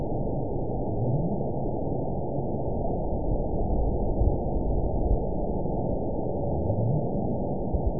event 914169 date 04/29/22 time 20:35:27 GMT (3 years ago) score 9.39 location TSS-AB01 detected by nrw target species NRW annotations +NRW Spectrogram: Frequency (kHz) vs. Time (s) audio not available .wav